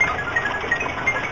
sndTokenSpinLoop.wav